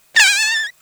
A weird noise that can only be described as "eeee"